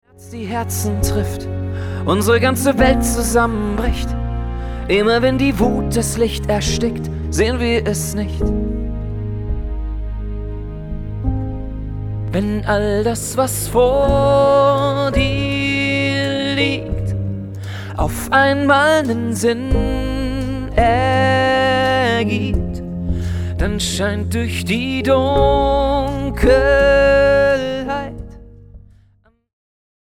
Mit Live-Aufnahmen der Premiere in der Essener Lichtburg.
Dazu Melodika, Akkordeon, Gesang und Looprecorder.